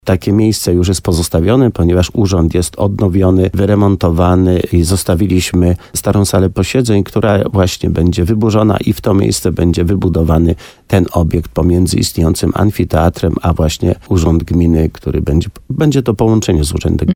Wybrano już lokalizację, w której może powstać inwestycja – mówi wójt Leszek Skowron.